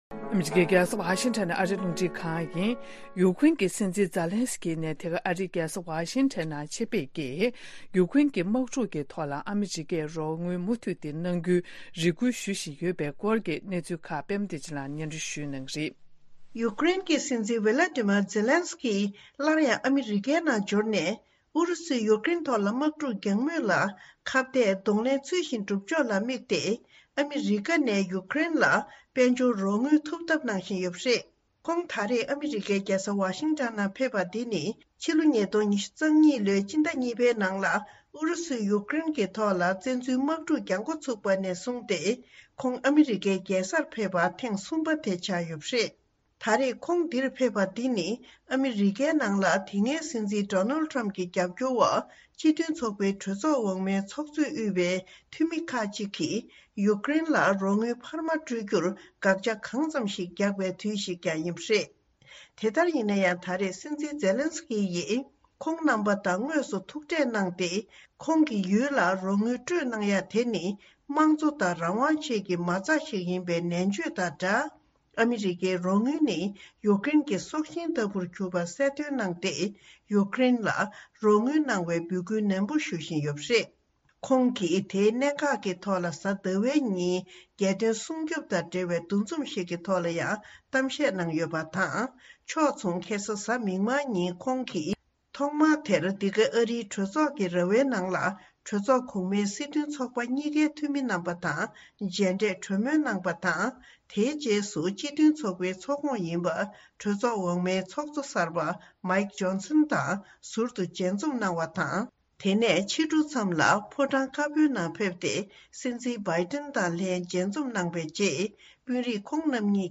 སྙན་སྒྲོན་ཞུས་རྗེས་མདོ་འཛོན་པ་དང་གླེང་མོལ་ཞུ་རྒྱུ།